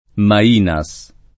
[ ma & na S ]